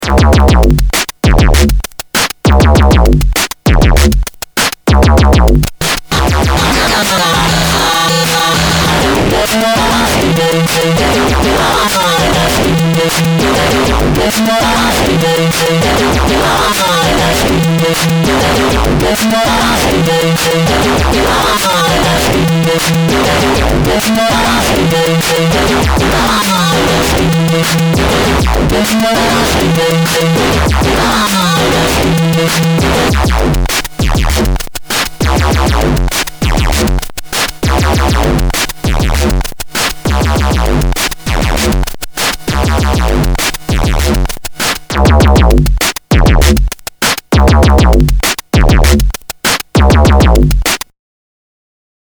sample six: po12 drum machine input then turning up the distortion and adding oscillator/sequencer.